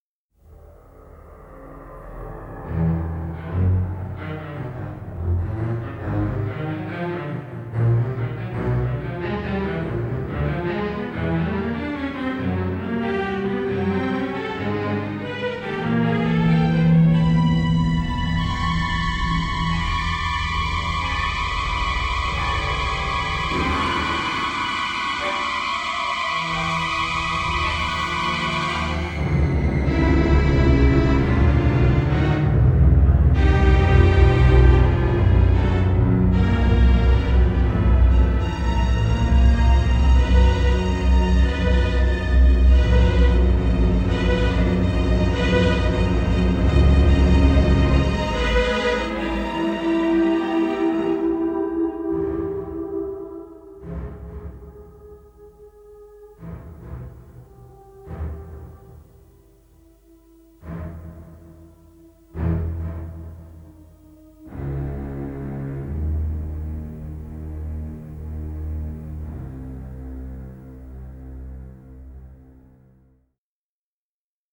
ELECTRONIC CUES